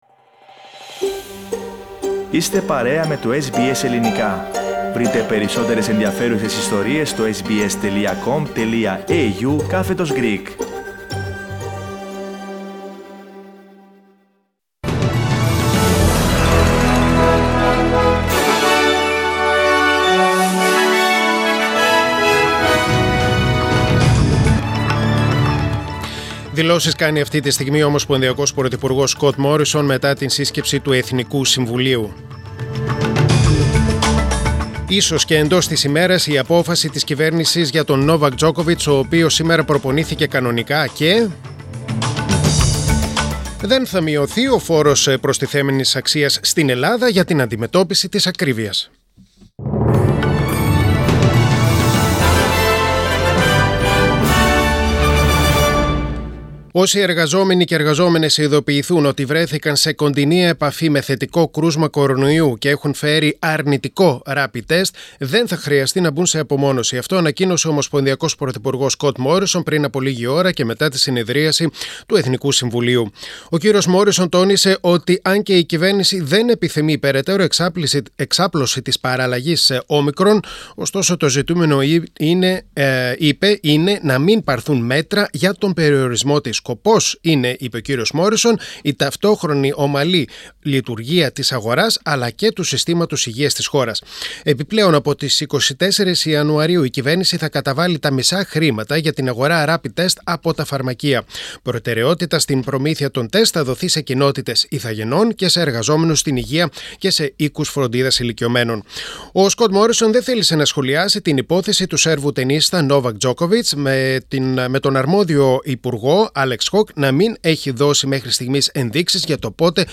Press Play on the main photo and listen the News Bulletin (in Greek) Share